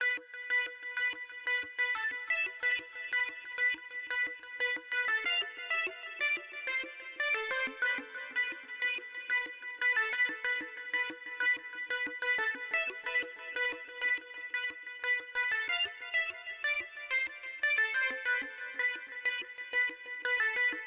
奇怪的拉链
描述：非常奇怪和不相干的合成器声音，基本上是一个带有失真和高通滤波器的方波，有一些LFO可以修改不同的参数，如振幅、截止点和共振。
标签： 外来 FX 噪声 腐臭 奇怪 合成器 非常-怪异 怪异
声道立体声